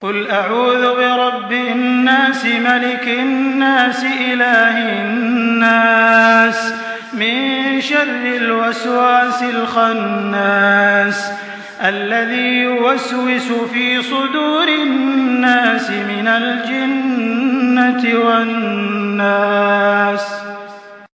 Surah Nas MP3 by Makkah Taraweeh 1424 in Hafs An Asim narration.
Murattal Hafs An Asim